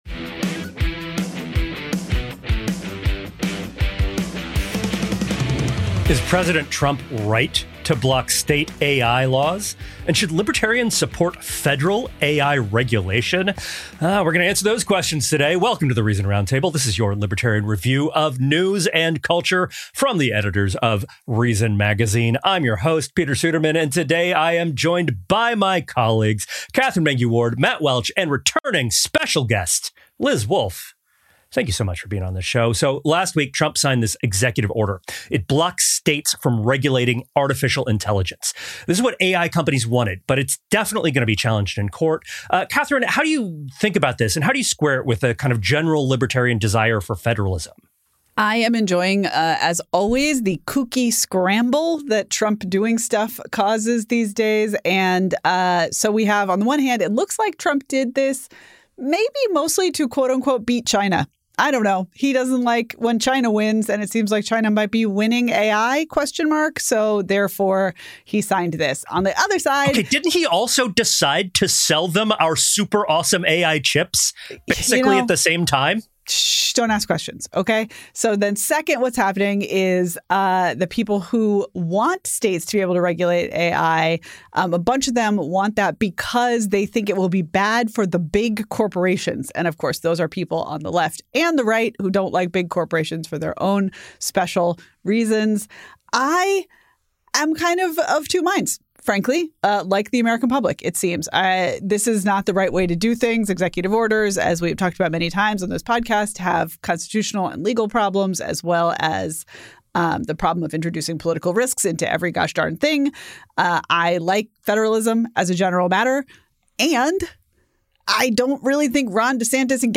discuss and debate the week’s biggest stories and what fresh hell awaits us all.